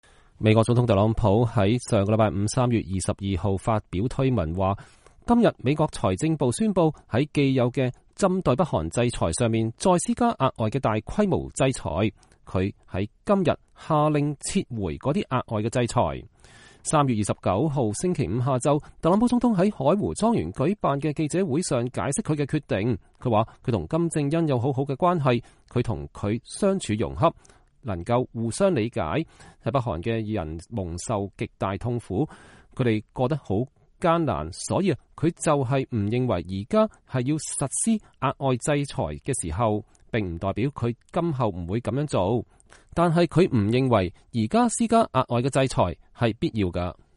3月29號星期五下午，特朗普總統在海湖莊園舉辦的記者會上解釋他的決定：“我跟金正恩有很好的關係。